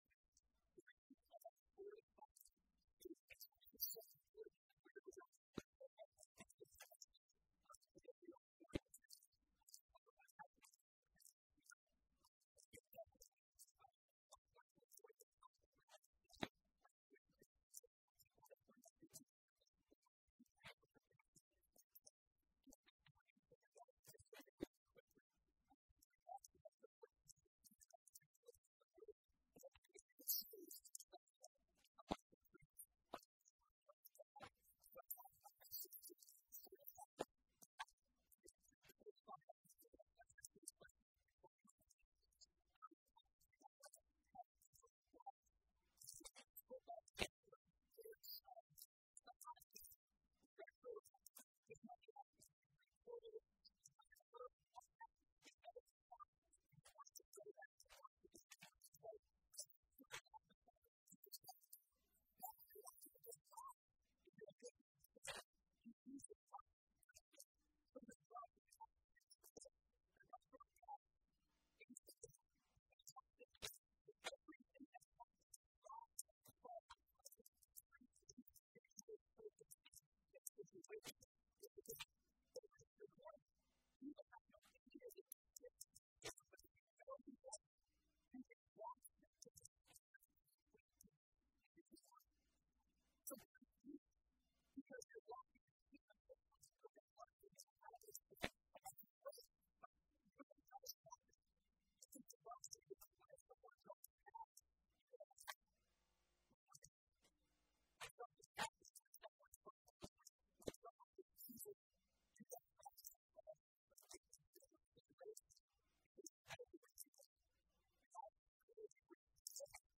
A message from the series "Fortified."